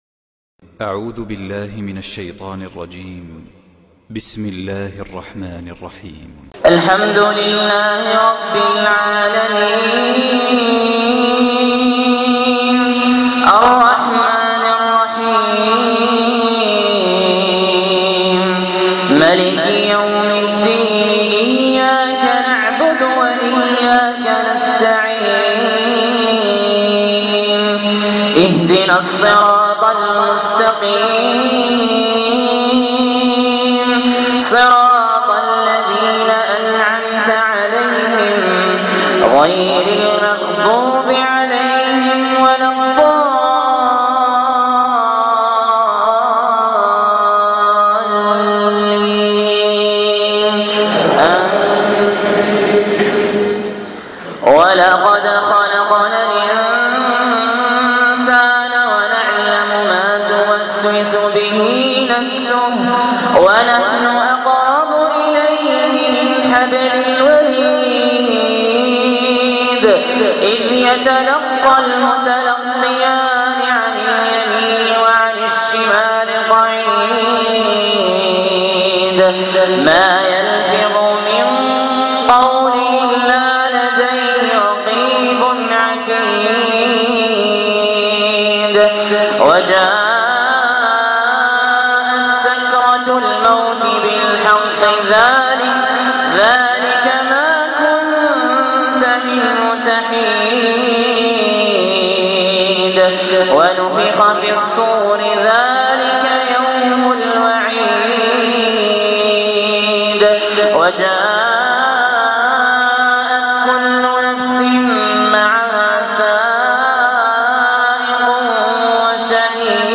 تلاوات خاشعة